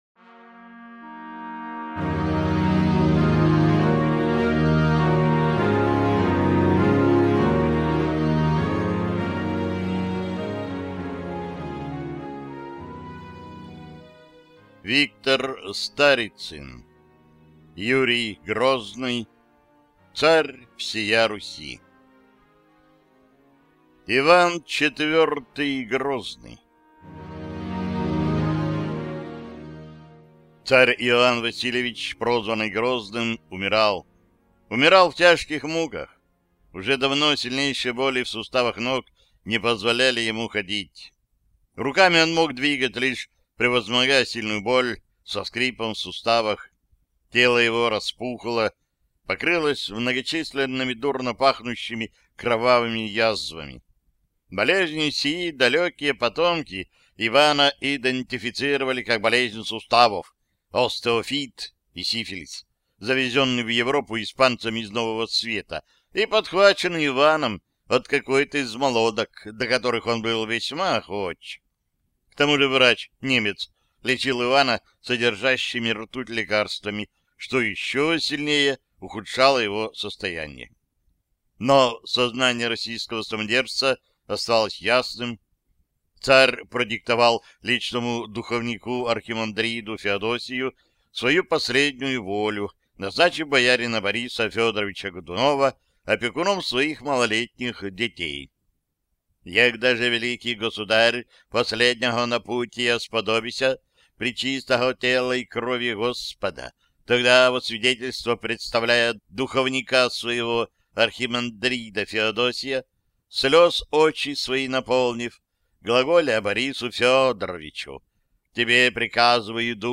Аудиокнига Юрий Грозный, Царь всея Руси | Библиотека аудиокниг